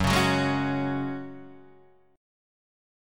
F# Suspended 2nd
F#sus2 chord {2 4 4 1 2 2} chord